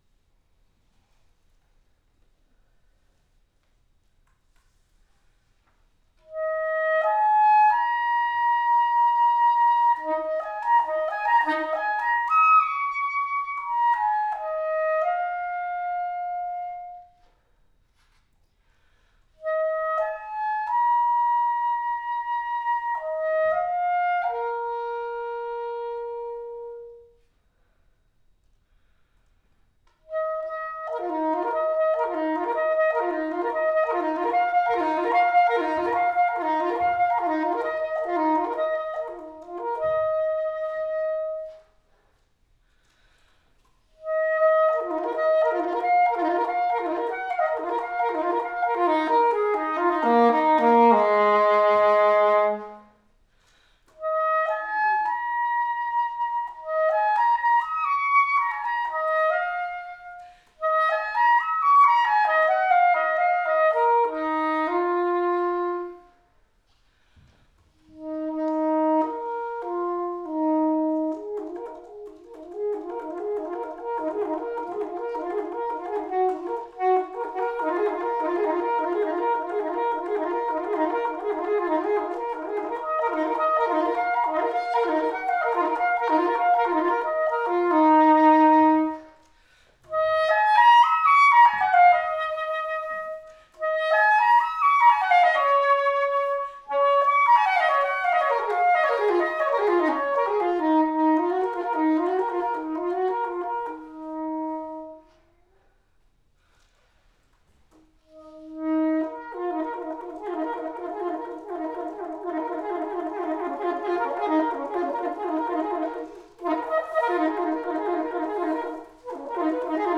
Jacob Ter Veldhuis’ Garden of Love is an excellent example of a work for soprano saxophone and electronics.
Saxophone, Jacob TV, Barry Cockcroft, Conrad Beck, Alfred Desenclos, Recital